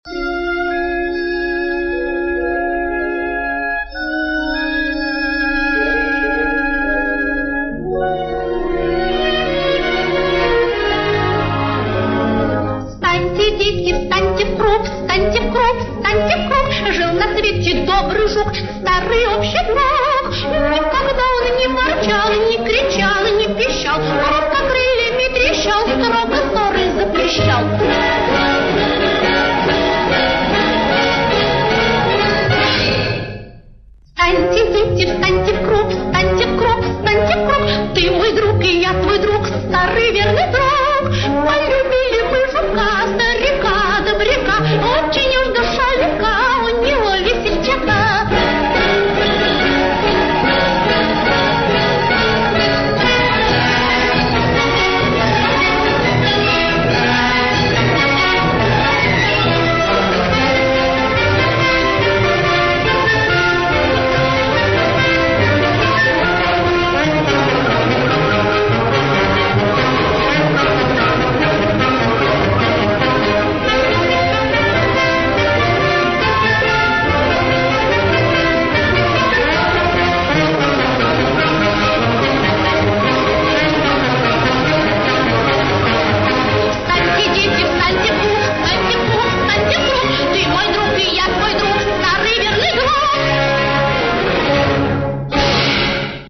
Главная » Музыка для детей » Детские песенки